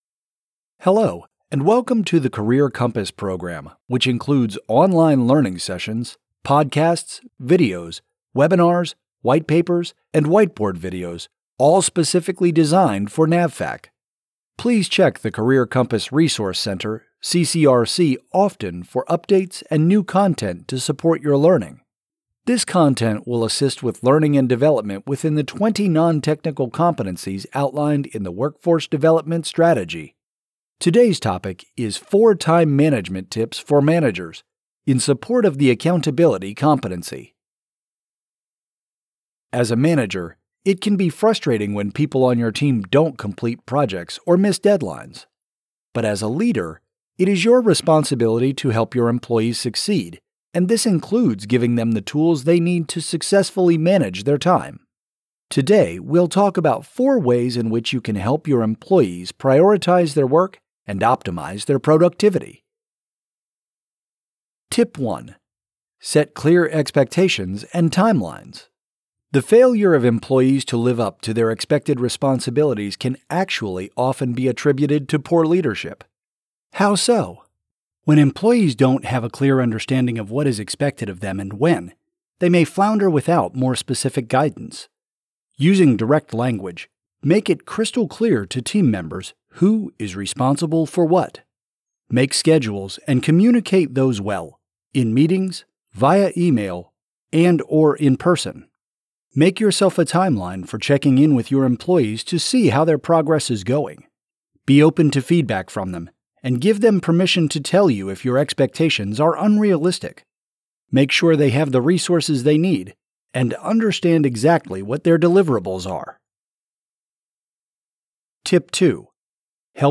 These 5 – 10 minute podcasts include facilitated discussions on select competency-related topics. They contain tips and techniques listeners can learn and quickly apply on-the-job.